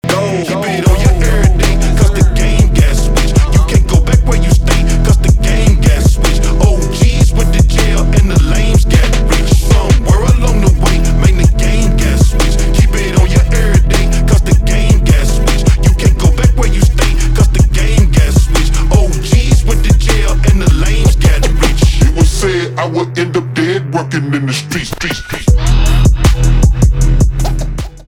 рэп
хип-хоп
басы